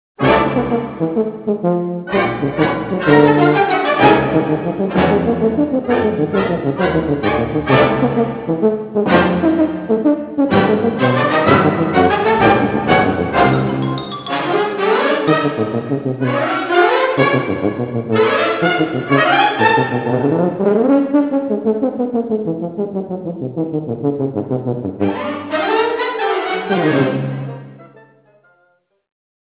tuba 2
bassoon 5